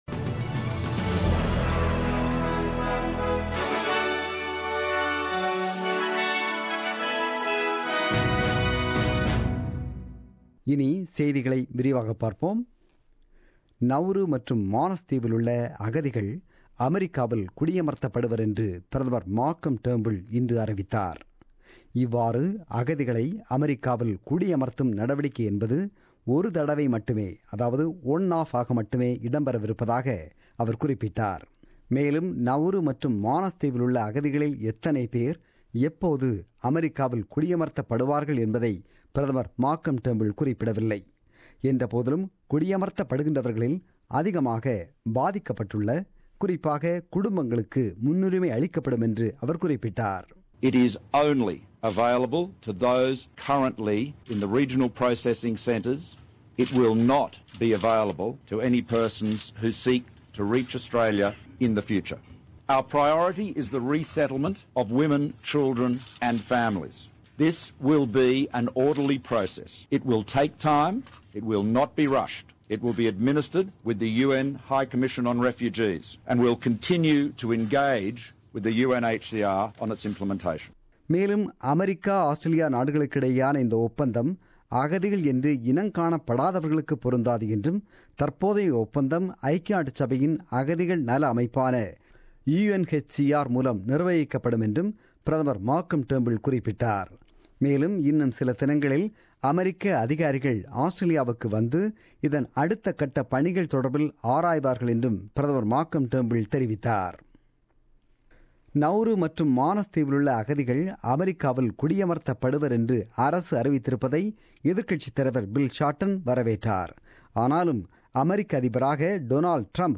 The news bulletin broadcasted on 13 Nov 2016 at 8pm.